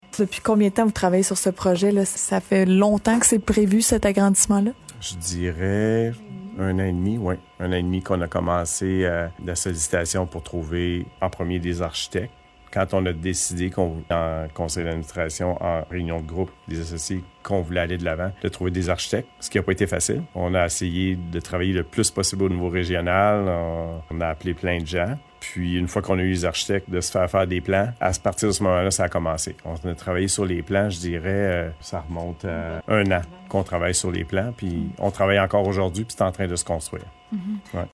ENTREVUE-2.3.3-CLINIQUE-VET_01.mp3